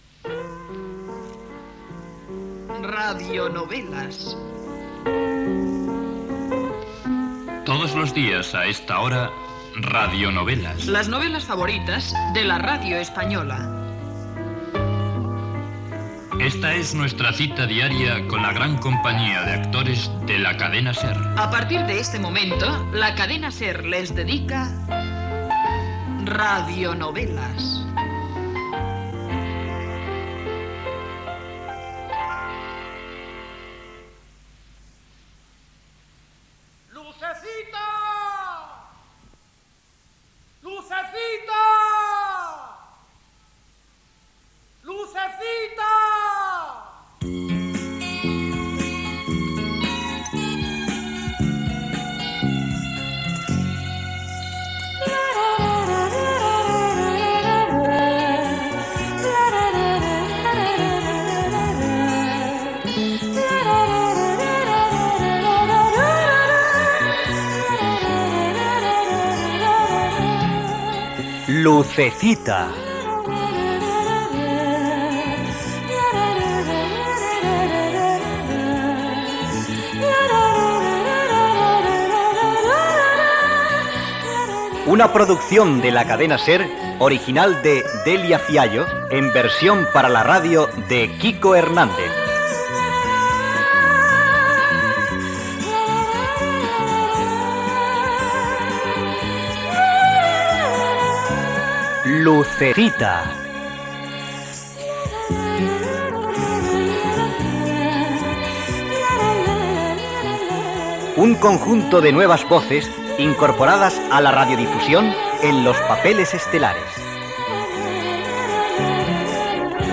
123045e4acaef4fa4551e9fd29d064b4d52149f1.mp3 Títol Cadena SER Emissora Ràdio Barcelona Cadena SER Titularitat Privada estatal Nom programa Lucecita Descripció Careta de "Radionovelas" i del serial "Lucecita", publicitat. Explicació del narrador: Marita ha millorat de salut i provoca que altres personatges marxin. Gustavo creu que Lucecita és la seva dona. Diàleg final entre Gustavo i Mirta.
Gènere radiofònic Ficció